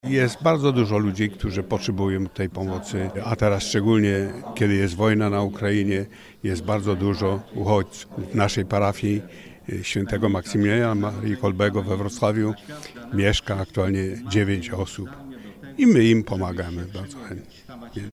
W sobotę o godzinie 10:00 rozpoczął się Zjazd Parafialnych Zespołów Caritas Archidiecezji Wrocławskiej.